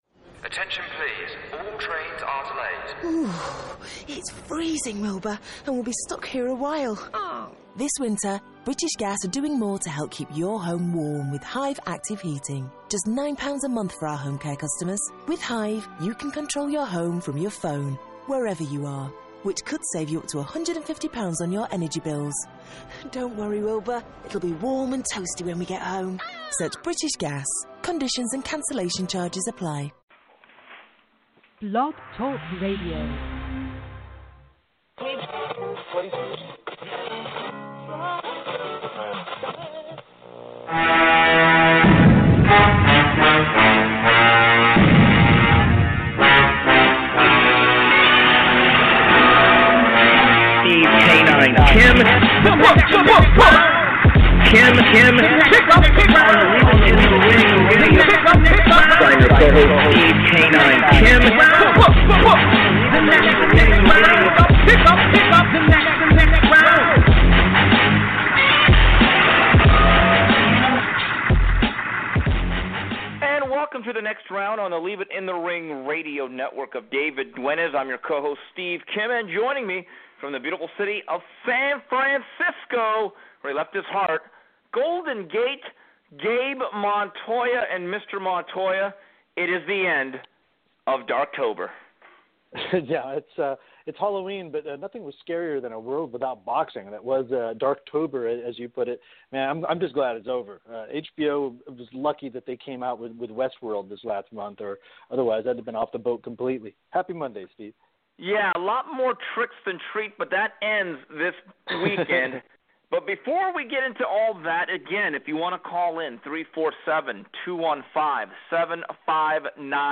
Plus, News & Notes and questions from callers and twitter.